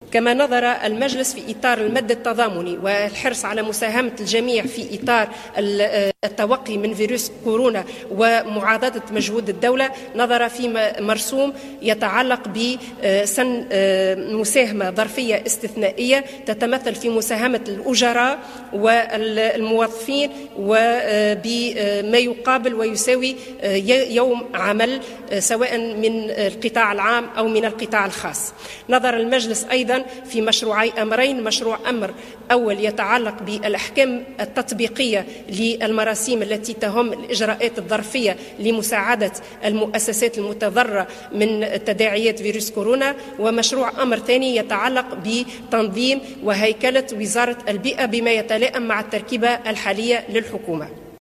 أكدت الناطقة الرسمية باسم الحكومة أسماء السحيري في تصريح حول أهم المراسيم المصادق عليها في أشغال مجلس الوزراء المنعقد أمس الإثنين بإشراف رئيس الحكومة إلياس الفخفاخ أن المجلس نظر في مرسوم يتعلق بسن مساهمة استثنائية ظرفية تتمثل في مساهمة الأجراء و الموظفين بما يساوي يوم عمل سواء في القطاع العام أو الخاص.